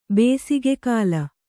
♪ bēsige kāla